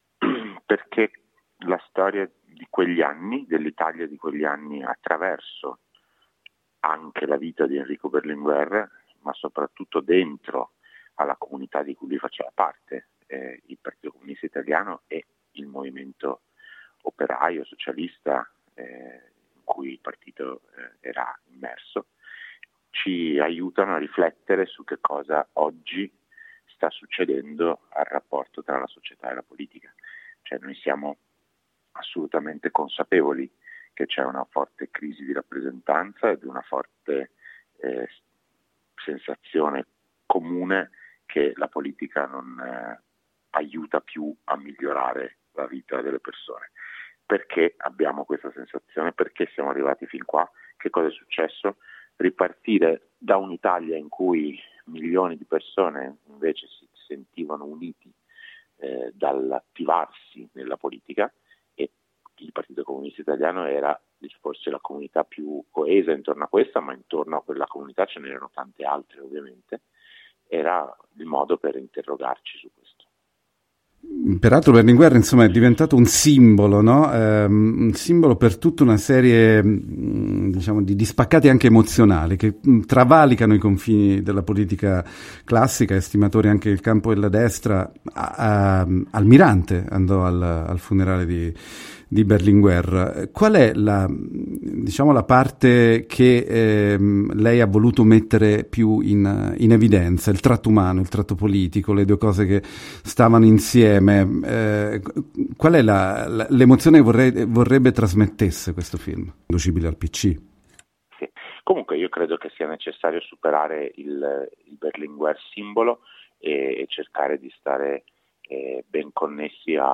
Andrea Segre, regista del recente successo Berlinguer, la grande ambizione, arriva domenica 15 dicembre a Firenze e provincia per un mini tour di incontro con il pubblico cinematografico. Lo abbiamo intervistato Il film, forte del successo di mezzo milione di spettatori cinematografici, con 11mila spettatori a Firenze città (e altri 5mila in provincia) prende le mosse dai primi anni Settanta e racconta, interpretato da Elio Germano, la storia di un uomo e di un popolo per cui vita e politica, privato e collettivo, erano indissolubilmente legati.